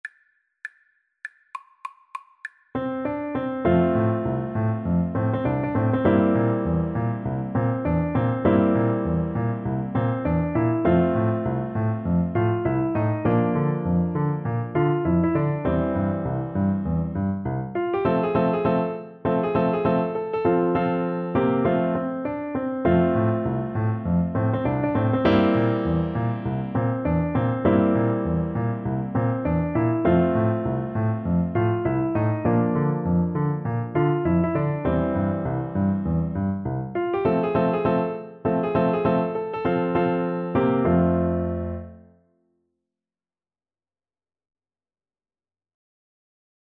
F major (Sounding Pitch) (View more F major Music for Voice )
Quick two in a bar = c.100
down_by_the_bay_VOICE_kar1.mp3